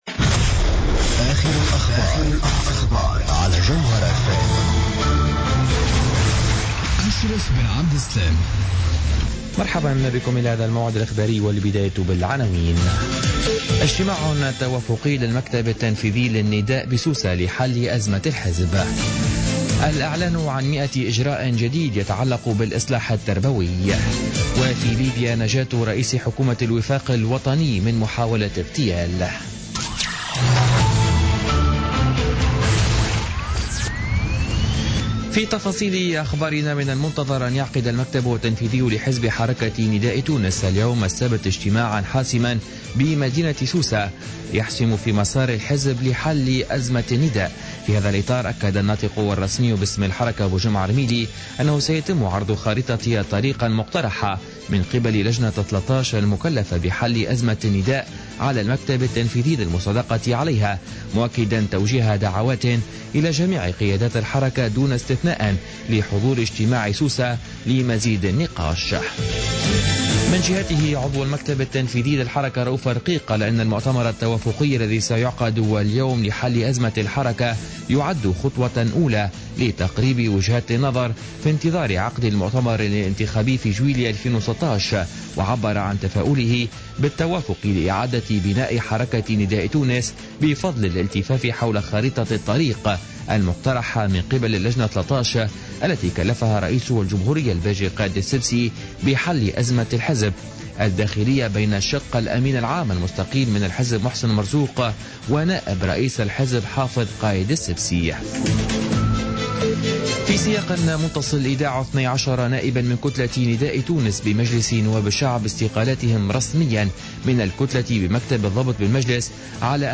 Journal Info 00:00 du samedi 09 Janvier 2016